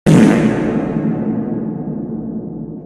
reverb fart 2024-01-01 14:23:21 +01:00 34 KiB Raw History